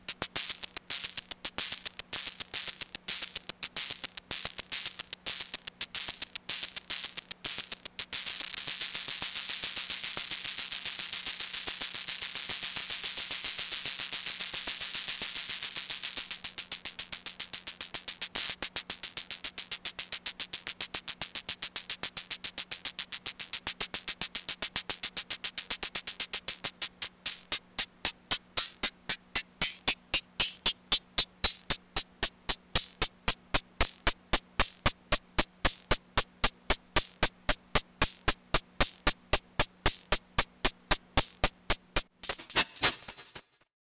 It sounds a little more metallic with the grain turned up as you can hear… when I turn the grain up, but OP says he wants volca beats hats out of the A4 and on their best day the volca beats hats sound like a wounded pepper shaker.
Sounds awesome, throw a stereo delay on there with the delay times offset and then we are talking!